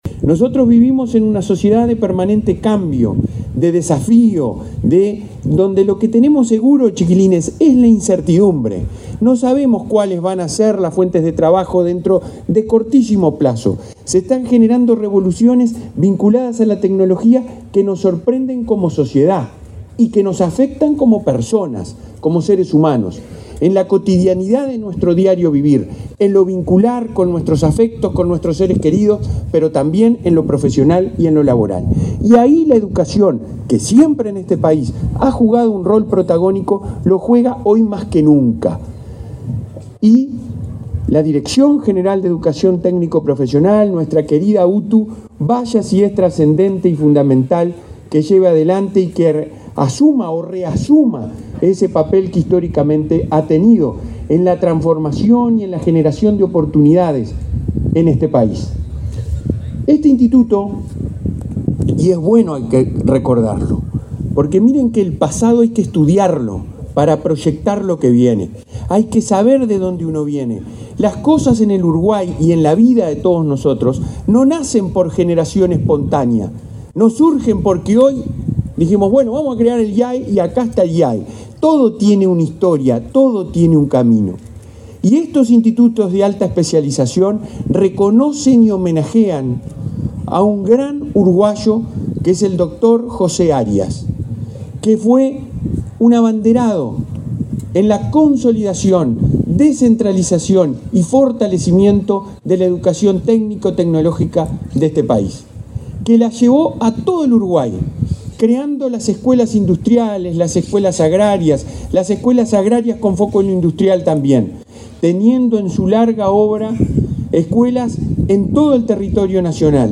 Palabras de autoridades de ANEP
El presidente de la Administración Nacional de Educación Pública (ANEP), Robert Silva, y el director general de la UTU, Juan Pereyra, participaron ene
Palabras de autoridades de ANEP 10/08/2023 Compartir Facebook X Copiar enlace WhatsApp LinkedIn El presidente de la Administración Nacional de Educación Pública (ANEP), Robert Silva, y el director general de la UTU, Juan Pereyra, participaron ene la inauguración del Instituto de Alta Especialización de Montevideo, en la zona de Vista Linda.